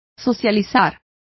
Complete with pronunciation of the translation of socialize.